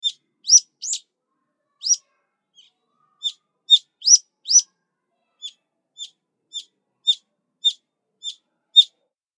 ヒヨドリ1.mp3